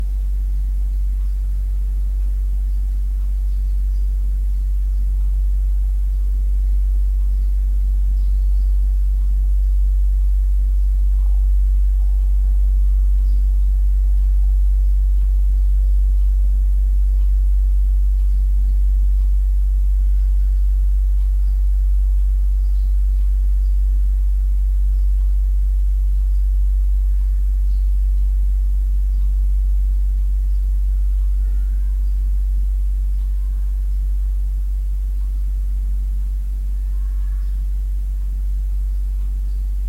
I checked into the hotel and almost immediately noticed a humming noise in my room.
Close to the walls, I measured the hum at over 60 decibels (in the 63-Hz band), sometimes reaching >65 dB.
It turned out to be humming noise peaking at 50 Hz.
50-Hz Hum in a quiet room
This is what the microphone picked up close to the wall:
Low-frequency-humming-noise.mp3